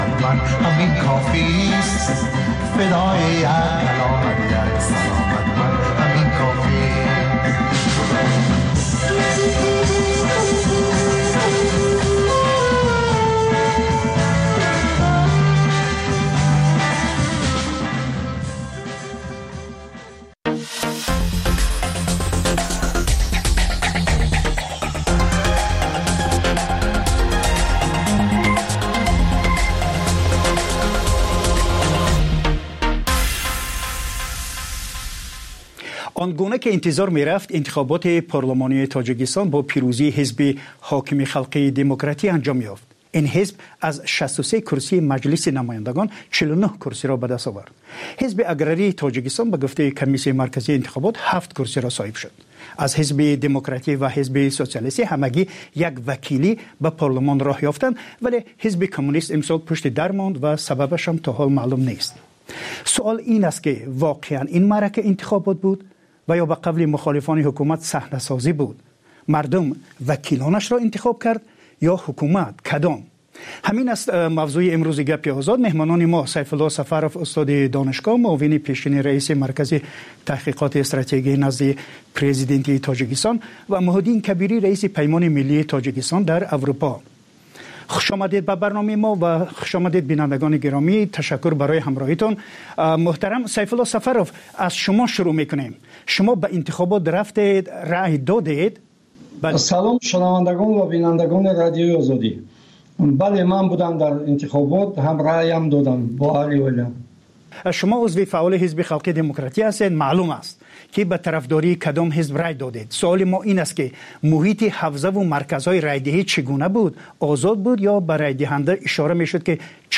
Пахши зинда
Маҷаллаи хабарӣ